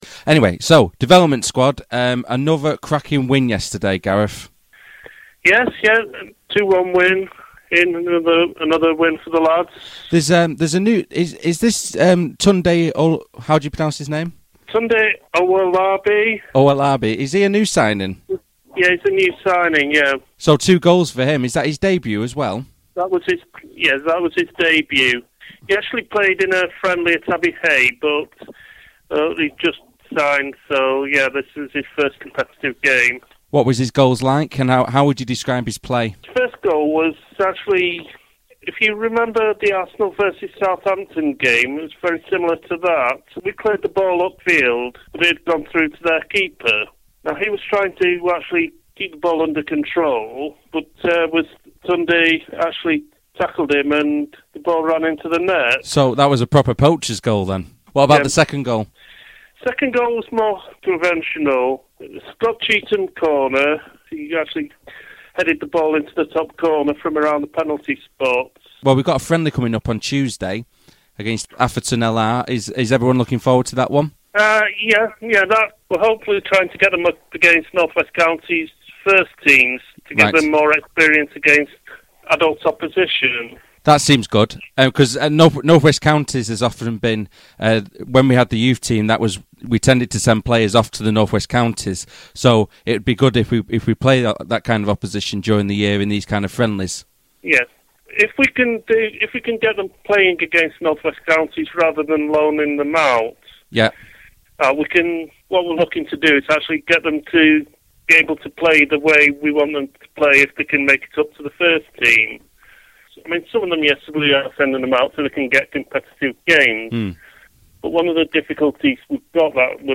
Post Match Interview - Hyde FC (h) - Development Squad